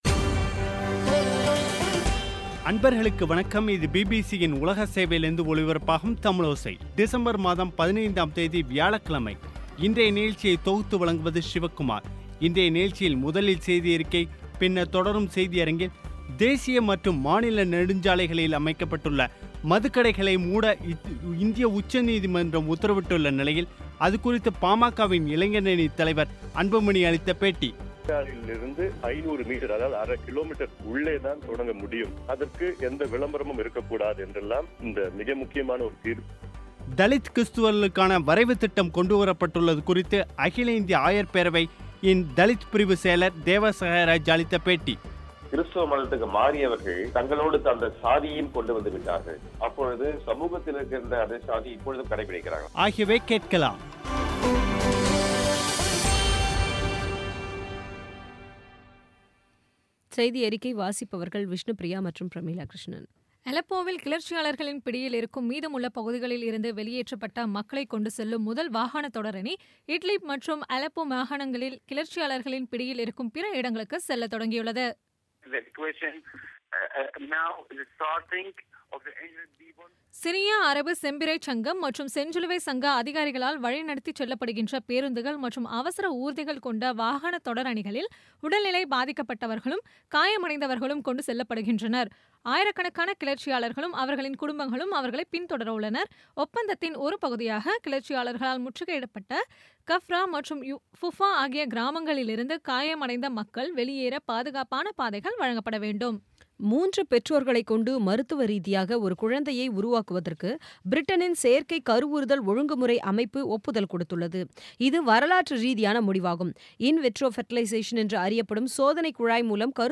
இன்றைய நிகழ்ச்சியில் முதலில் செய்தியறிக்கை, பின்னர் தொடரும் செய்தியரங்கில்
தேசிய மற்றும் மாநில நெடுஞ்சாலைகளின் ஓரமாக அமைக்கப்பட்டுள்ள மதுக்கடைகள் அனைத்தையும் மூட கூறி இந்திய உச்சநீதிமன்றம் உத்தரவிட்டுள்ள நிலையில், அது குறித்து பாமகவின் இளைஞரணி தலைவர் அன்புமணி அளித்த பேட்டி